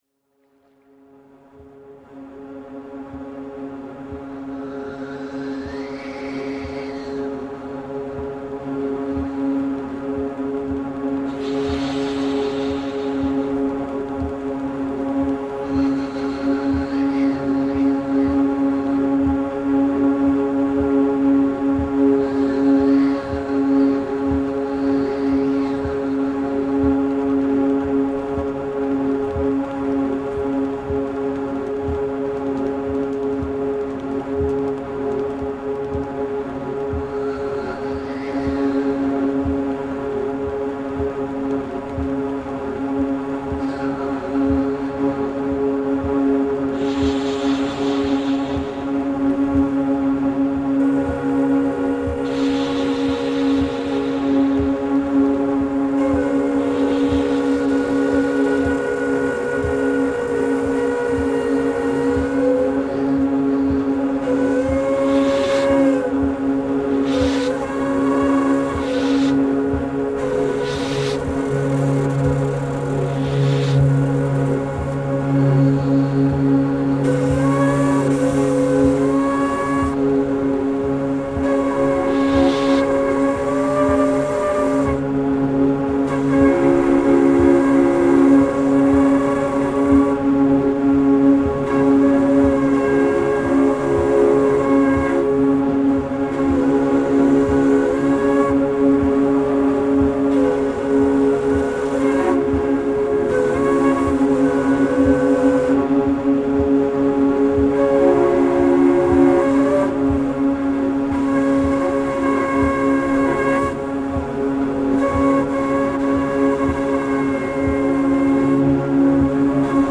prepared neys and shakuhach ,
tube drone- recording engineer
filtered ocean